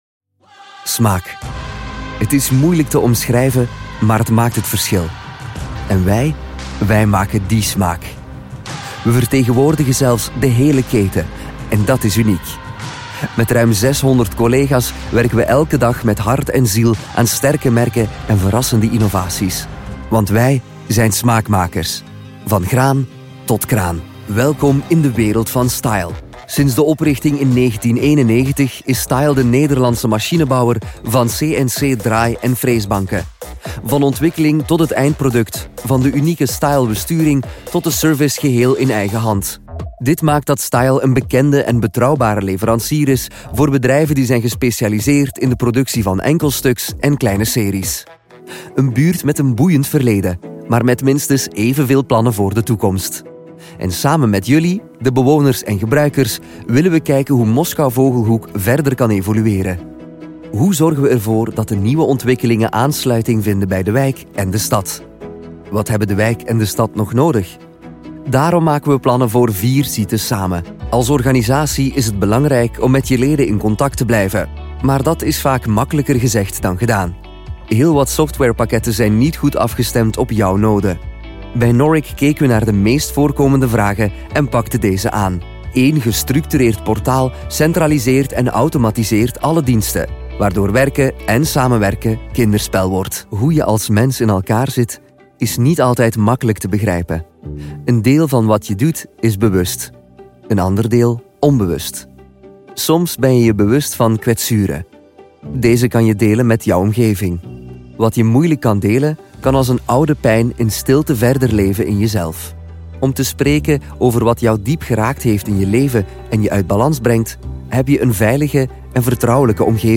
flämisch
Sprechprobe: Industrie (Muttersprache):